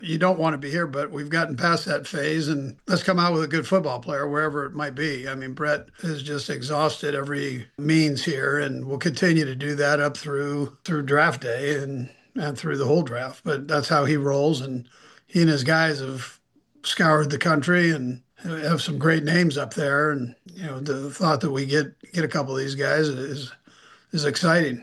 Head coach Andy Reid shared a brief update on Mahomes during a Zoom call with local media.